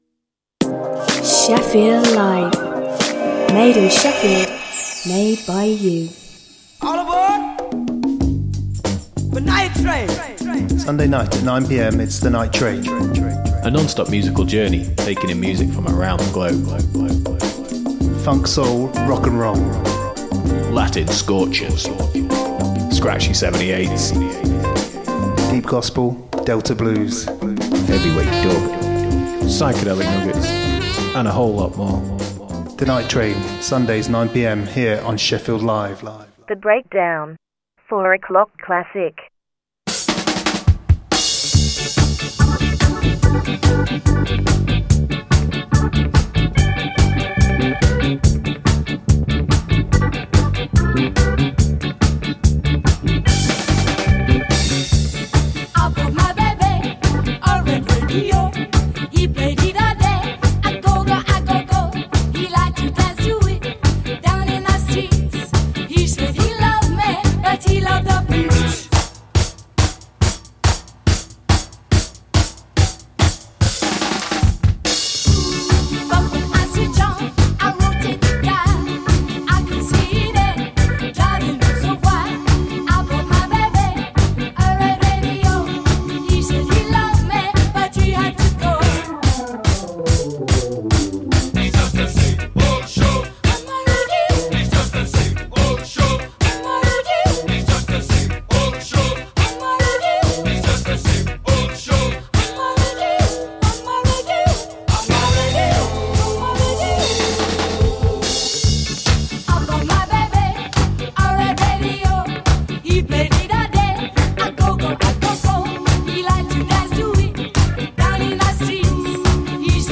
A weekly musical jamboree transmitting live to the world every Saturday afternoon.